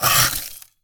ice_spell_freeze_small_03.wav